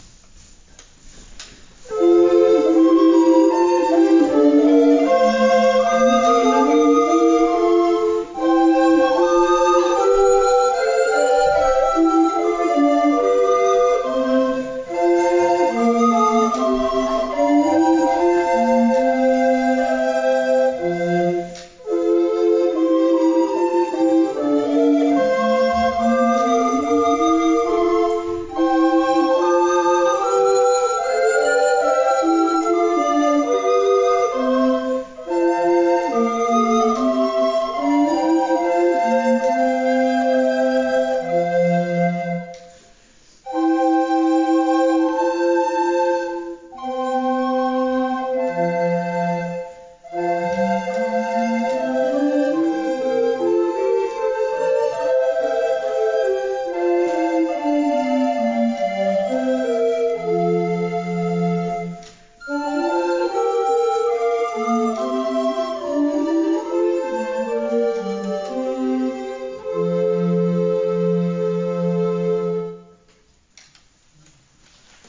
Hier können Sie gern einige Klangeindrücke unseres Ensembles gewinnen: